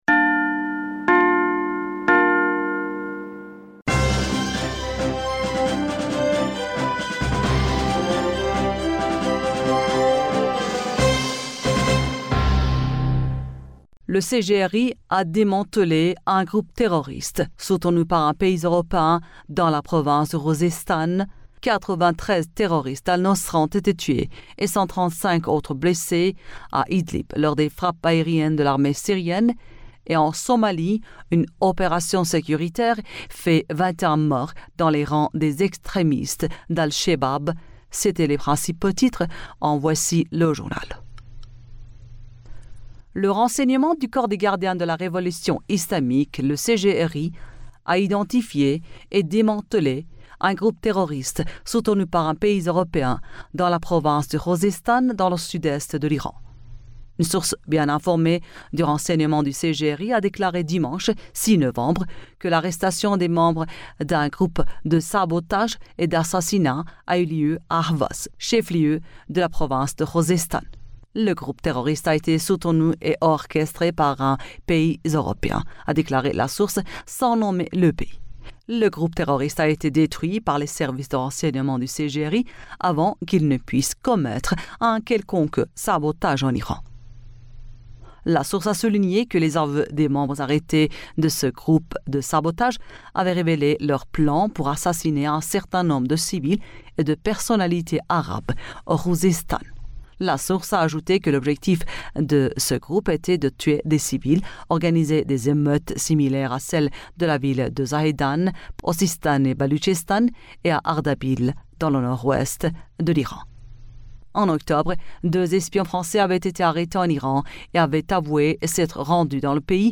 Bulletin d'information Du 07 Novembre